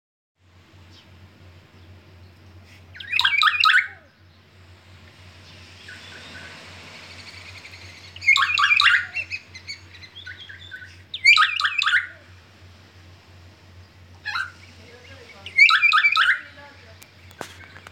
Kaka (Nestor meridionalis)
Nombre en inglés: New Zealand Kaka
Localidad o área protegida: Stewart Island
Condición: Silvestre
Certeza: Fotografiada, Vocalización Grabada